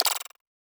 Twisted Mech Notification2.wav